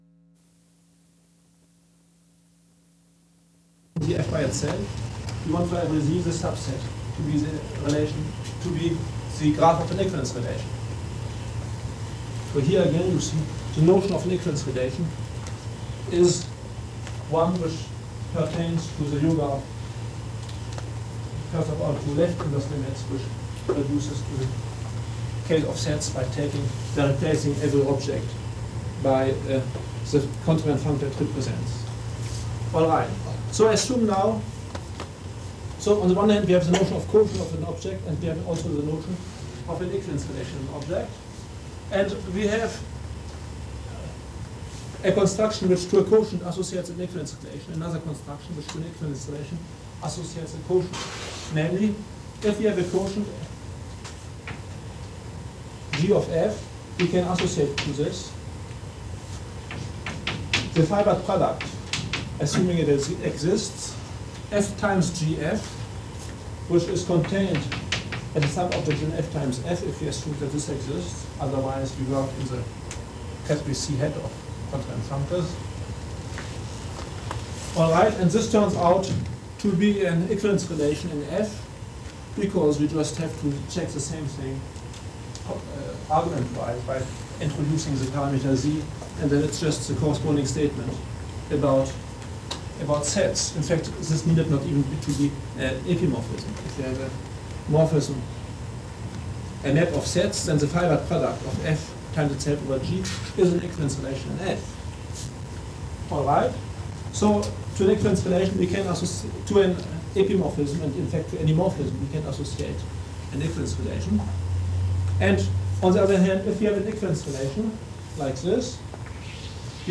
Here's a wav from Grothendieck in 1973, speaking English with a heavy German accent although he left Germany at the age of 6.
Interesting! is that after digital restauration?
Although more could be done to get rid of the noise, but it is pretty understandable already.
The quality is fine, yes!